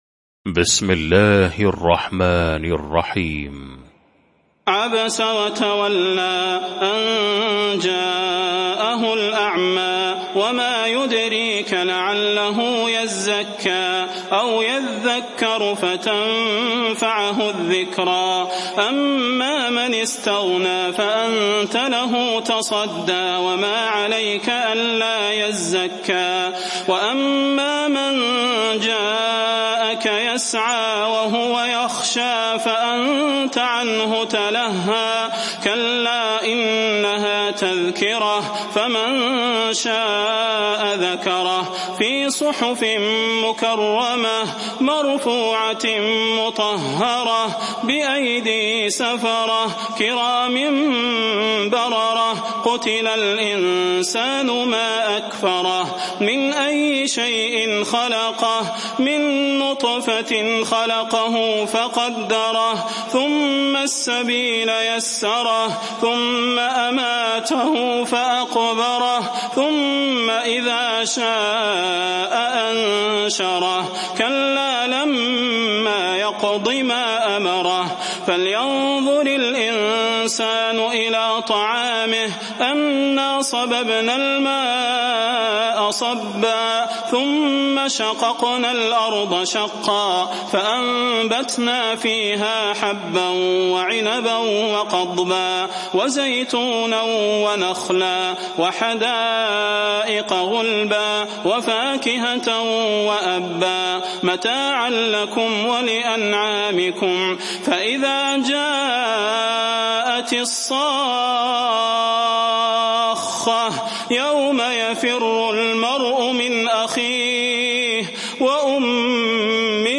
المكان: المسجد النبوي الشيخ: فضيلة الشيخ د. صلاح بن محمد البدير فضيلة الشيخ د. صلاح بن محمد البدير عبس